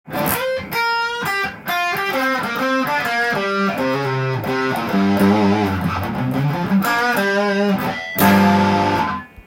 音の方は、歪ませるとかなりギンギンになりますので
試しにSG500を弾いてみました
リアピックアップで弾くとかなり歪むのがわかります。ヘビメタもいけそうなサウンドです！特に低音が良いですね！